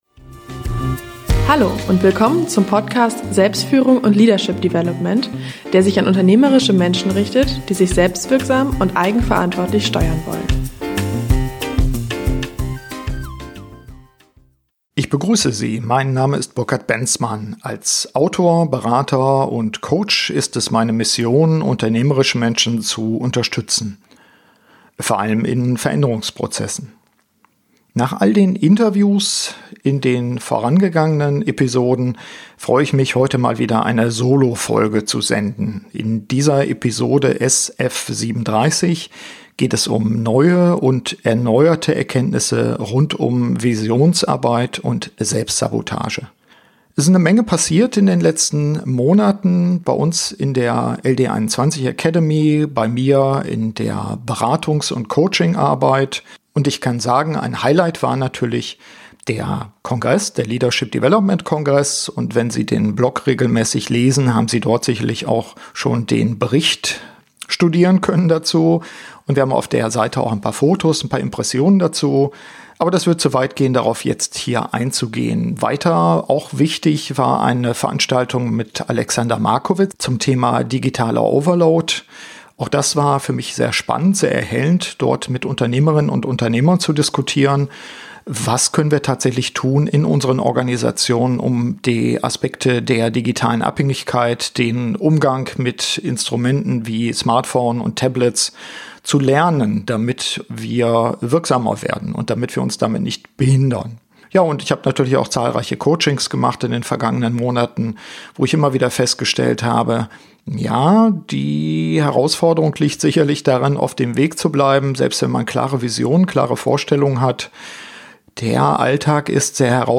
Nach all’ den Interviews freue ich mich, heute wieder einmal eine Soloepisode zu senden. In der Episode SF37 geht es um neue und erneuerte Erkenntnisse rund um Visionsarbeit und Selbstsabotage.